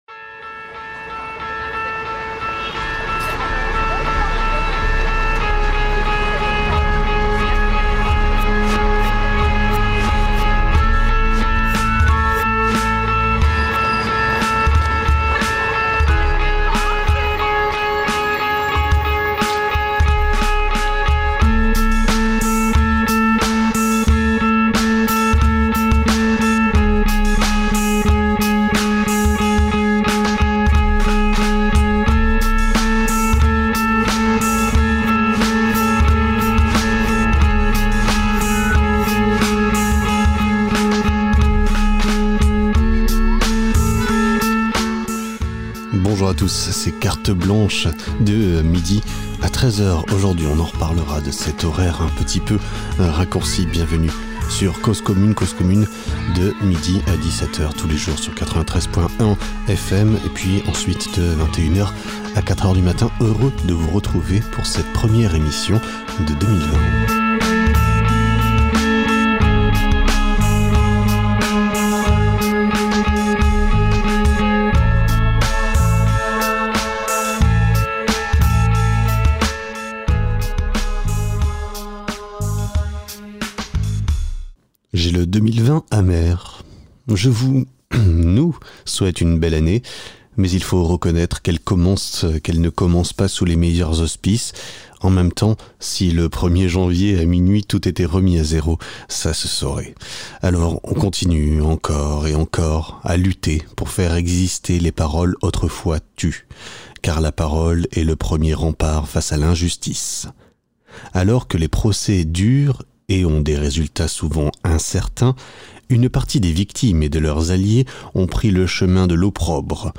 Depuis début décembre, plusieurs radios de la France entière se sont réunies pour émettre ensemble le temps des manifestations sous le nom de l’Acentrale. Des heures et des heures de direct depuis différents points de la carte de France auxquelles nous rendons hommage sous la forme d’une sorte de best ofs, picoré çà et là lors des longues périodes de direct les 9 et 11 janvier.